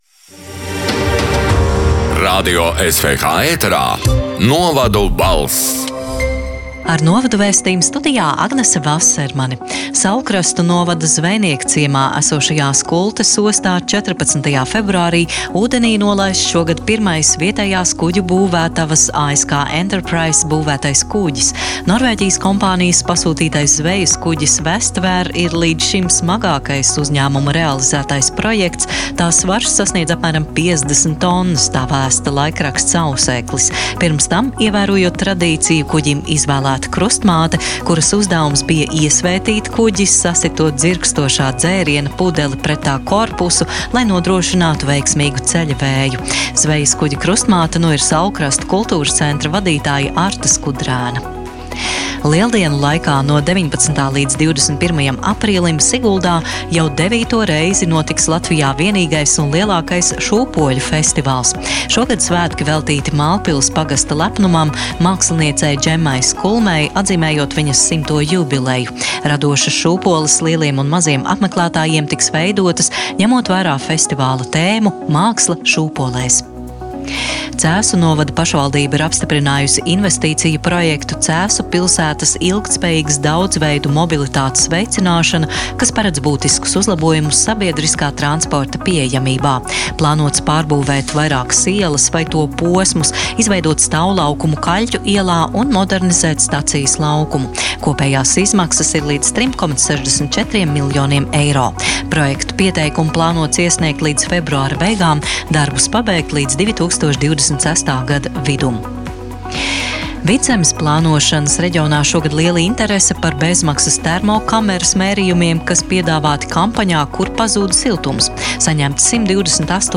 “Novadu balss” 19. februāra ziņu raidījuma ieraksts: